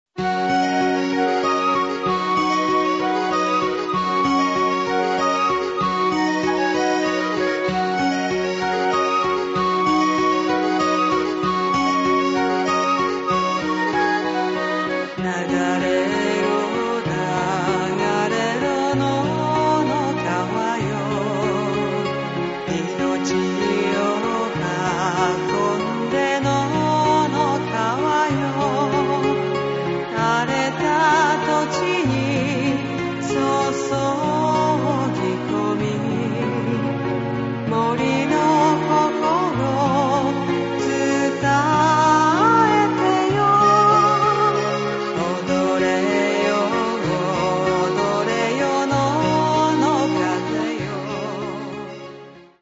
• ファイルサイズ軽減のため、音質は劣化しています。
シンセサイザー演奏